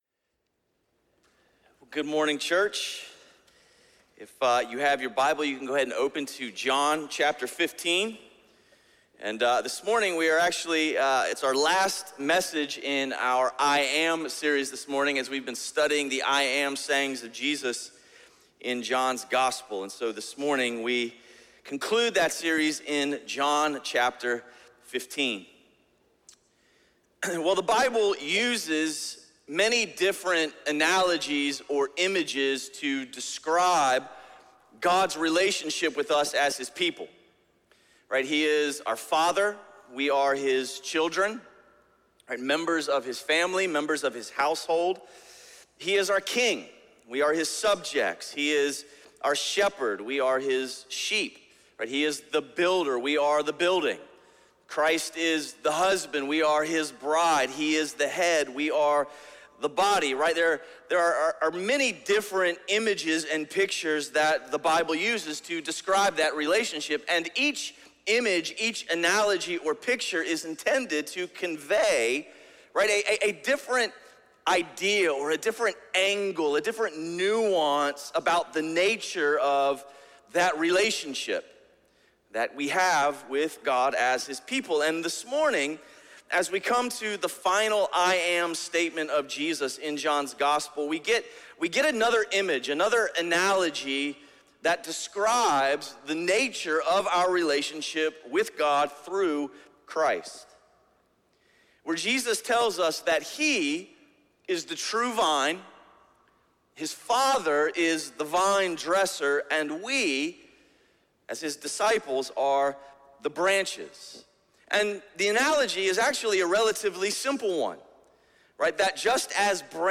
A message from the series "1-1-Six."